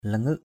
/la-ŋɯʔ/ (cv.) lingâk l{zK (t.) ngất = perdre connaissance. lose consciousness, fainted. ataong langâk a_t” lzK đánh ngất. langâk sa banrok lzK s% b_nK ngất một lát.